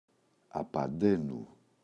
απανταίνω [apa’ndeno]
απανταίνου.mp3